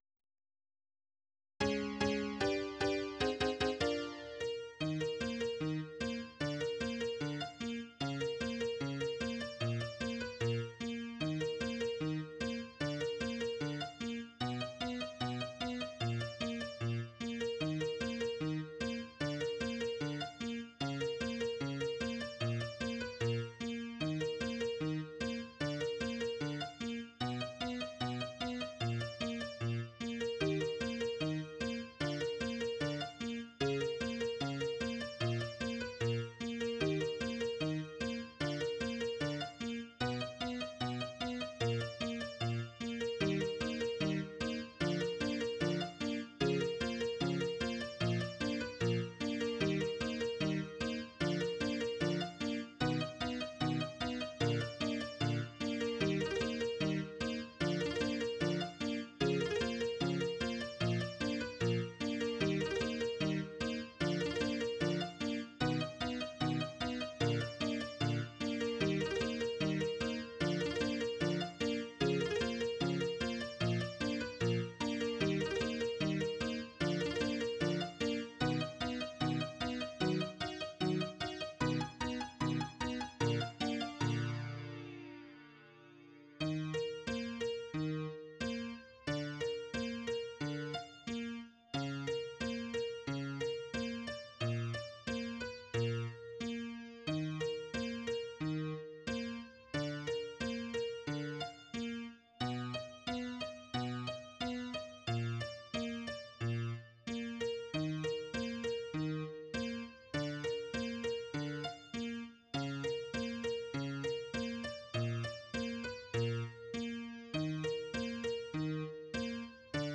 Honky-tonk retro style song